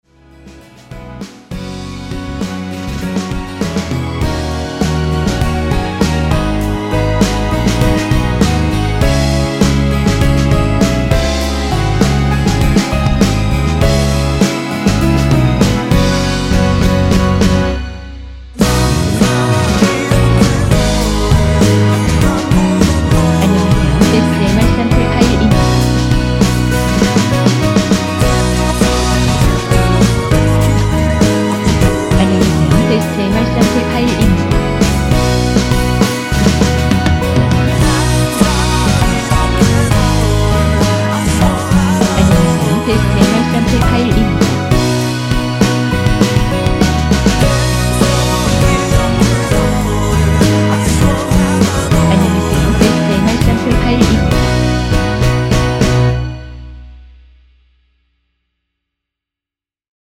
이곡은 원곡자체에 코러스가 마지막 부분만 나오며 미리듣기 부분의 코러스가 전부 입니다.
원키 코러스 포함된 MR입니다.
앞부분30초, 뒷부분30초씩 편집해서 올려 드리고 있습니다.